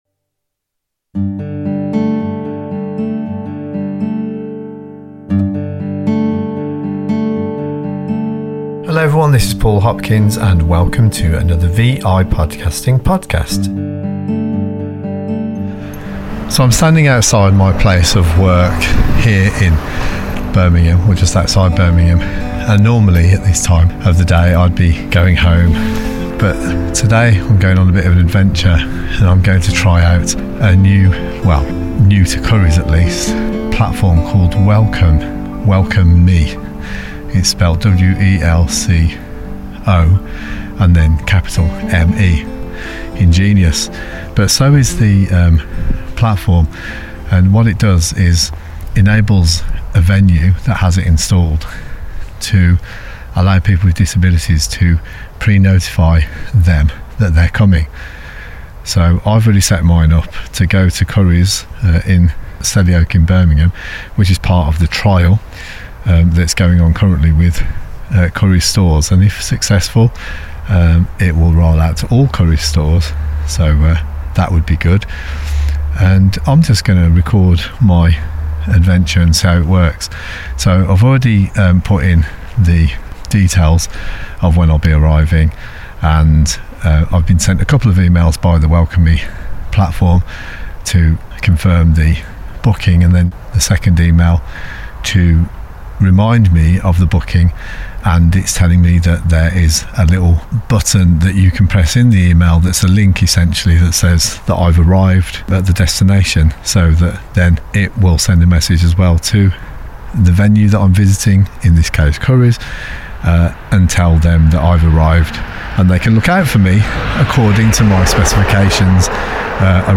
Join Pyrgo and I as we make a trip to one of the Curry’s Electrical stores involved in trialing WelcoMe, an ingenious platform which connects public venues to people with disabilities on their own terms.